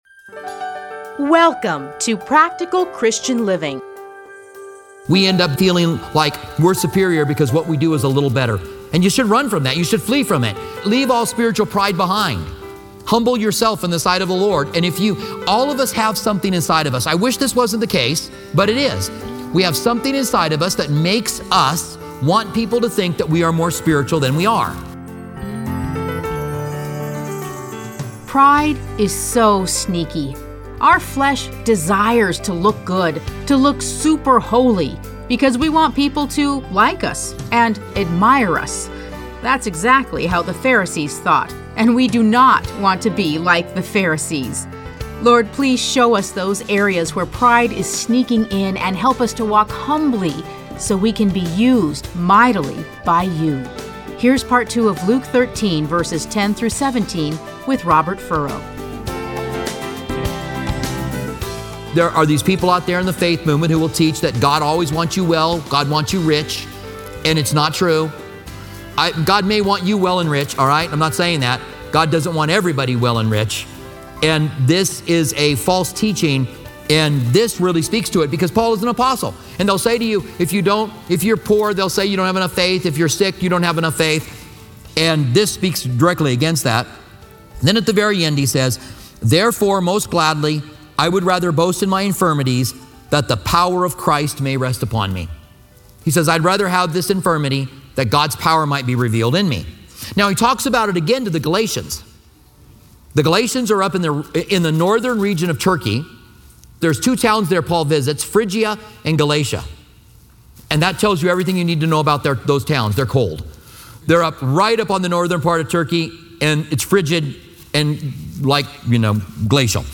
Listen to a teaching from Luke 13:10-17.